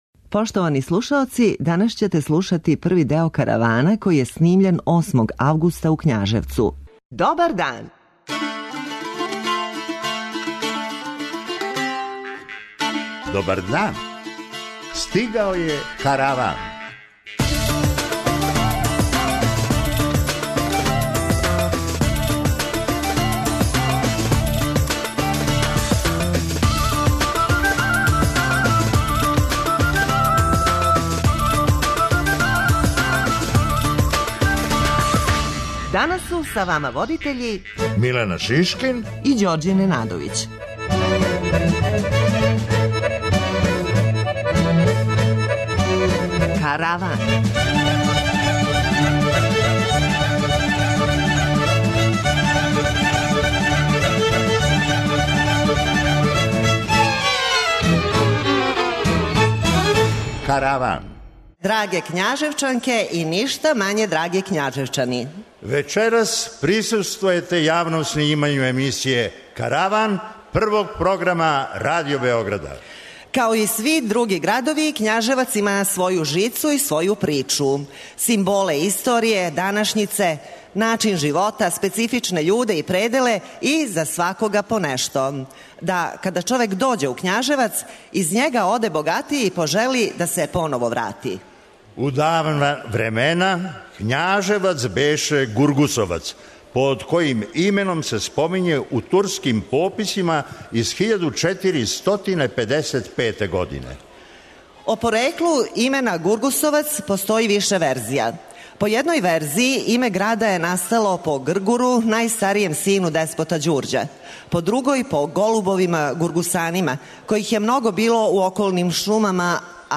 Данас имате прилику да слушате први део емисије, која је јавно снимљена у Књажевцу.